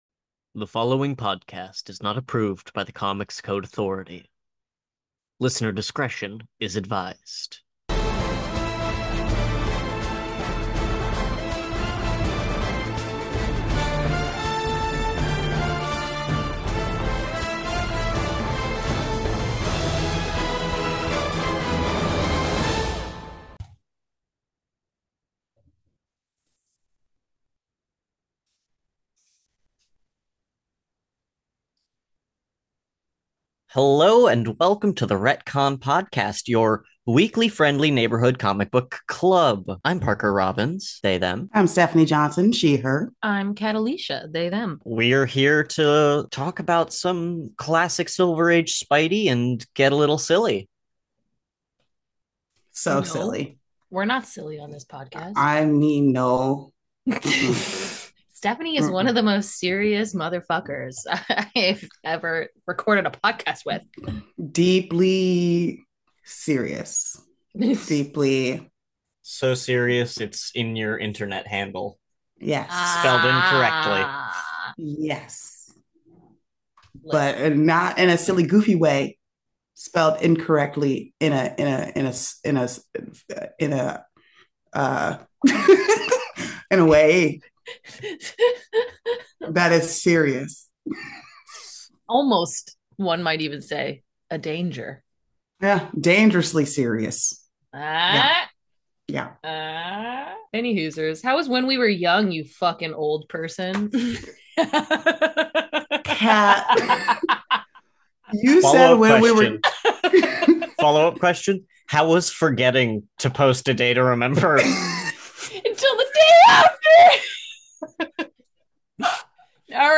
The Retcon Podcast is recorded in Los Angeles